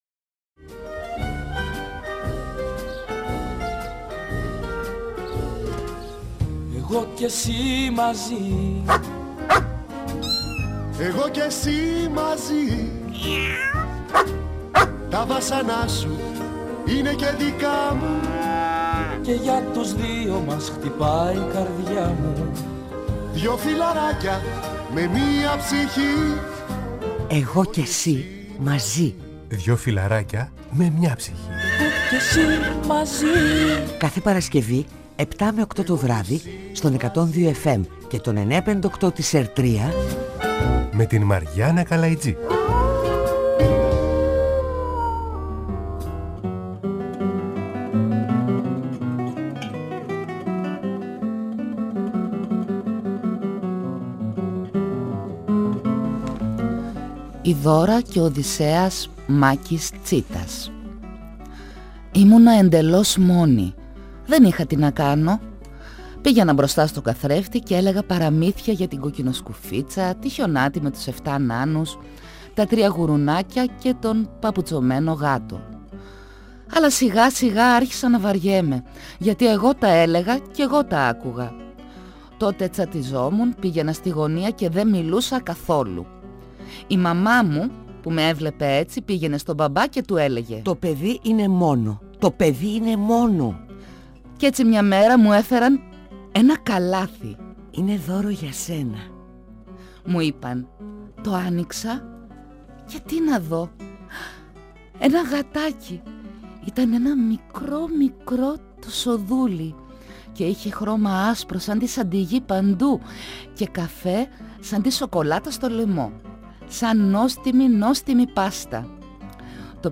Στην εκπομπή συνομιλήσαμε με τον συγγραφέα του «Αδέσποτου Κώστα» ..Μάκη Τσίτα… Το 2014 ο Μάκης Τσίτας πήρε το βραβείο Λογοτεχνίας της Ευρωπαϊκής Ένωσης για το βιβλίο του «Μάρτυς μου ο Θεός» το οποίο κυκλοφορεί σε 7 Ευρωπαϊκές γλώσσες και έχει μεταφερθεί με μεγάλη επιτυχία στη θεατρική σκηνή.